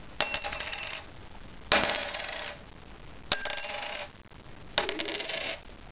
Geldstück, Münze
Abb. 01: Unterschiedlicher Klang der Münzen auf einer Keramikunterlage.